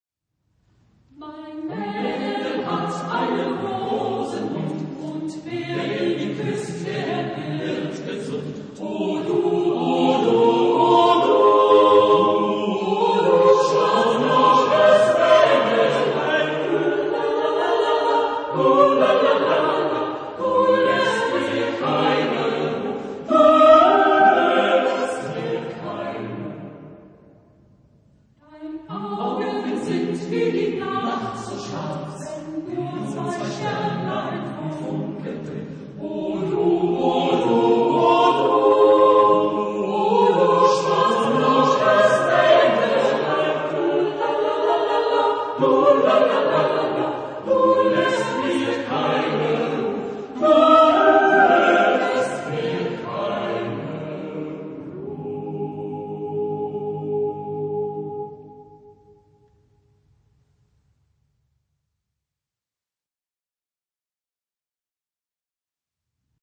Genre-Style-Forme : Chanson ; Folklore ; Profane
Type de choeur : SATB  (4 voix mixtes )
Tonalité : la bémol majeur